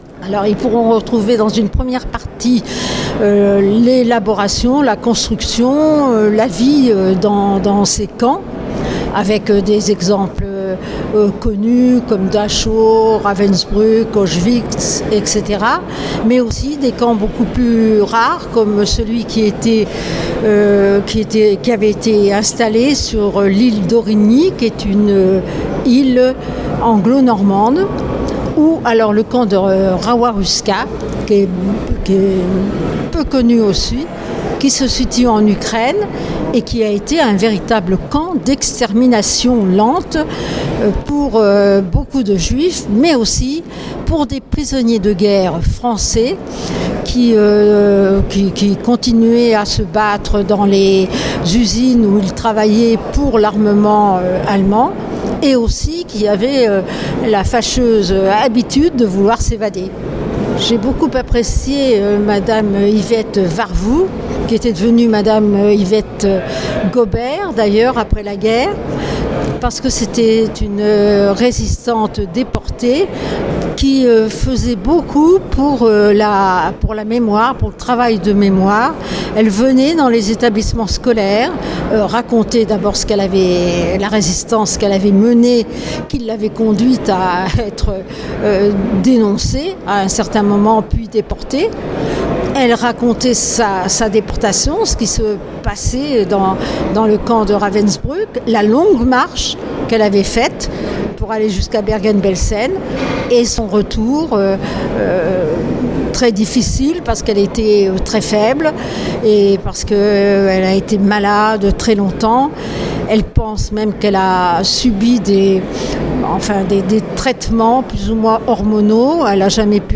12 avril 2022   1 - Interviews, 2 - Culture, 4 - Vie Publique